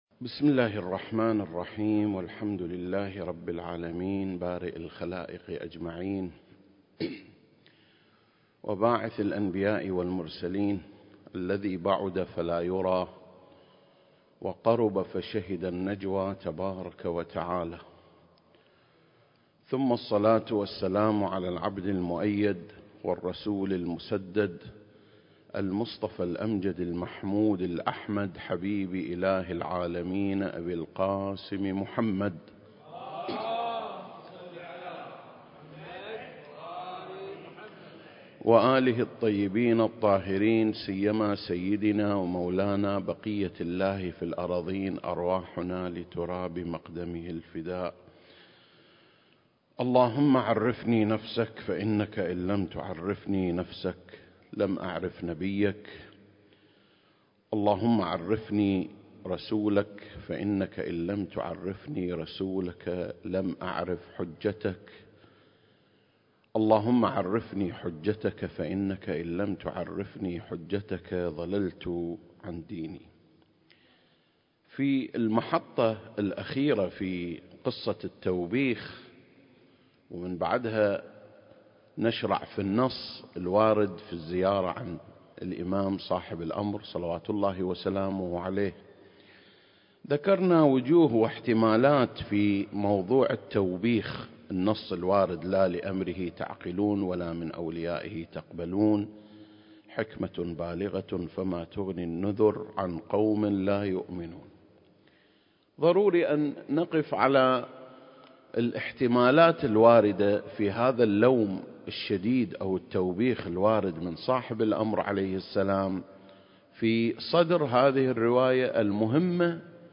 سلسلة: شرح زيارة آل ياسين (17) - قصة التوبيخ (5) المكان: مسجد مقامس - الكويت التاريخ: 2021